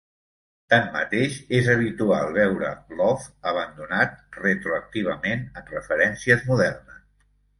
Pronounced as (IPA) [ˈe.lə]